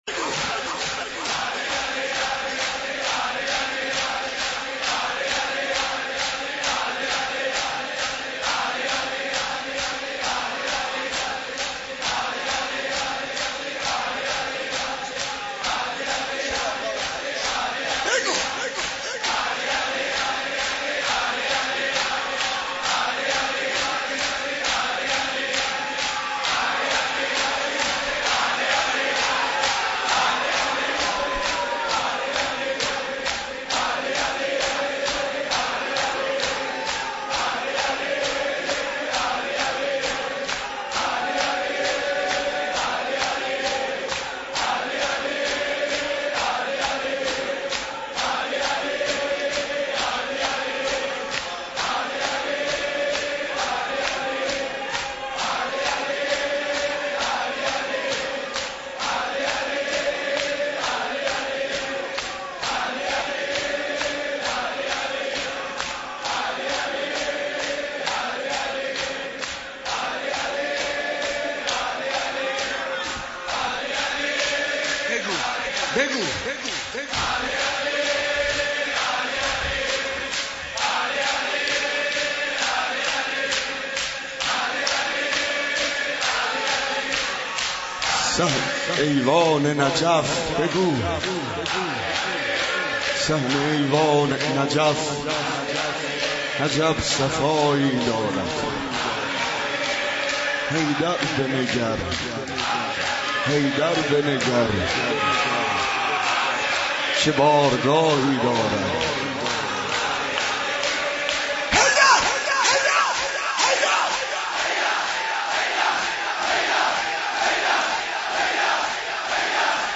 مراسم شب نوزدهم ماه رمضان با مداحی : حاج عبدالرضا هلالی - در حسینیه امام موسی بن جعفر علیه السلام برگزار گردید
سینه زنی زمینه ، شور